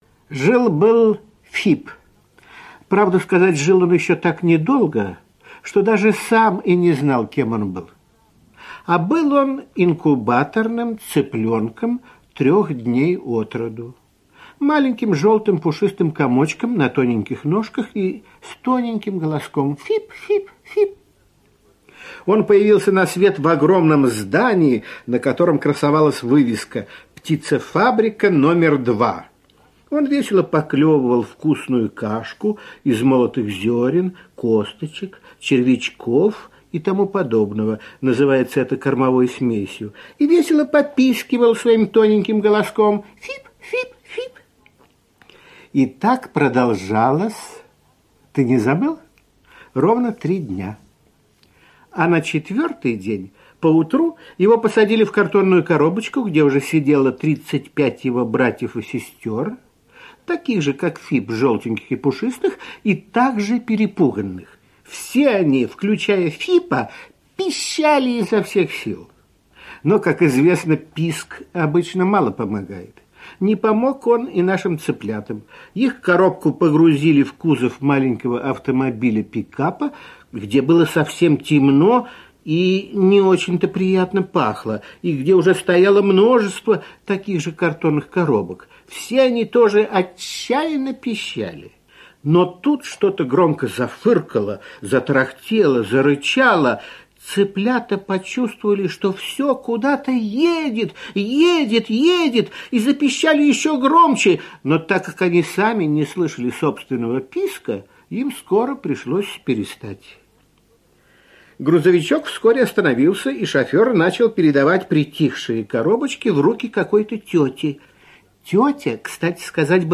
Жил-был Фип — слушать аудиосказку Борис Заходер бесплатно онлайн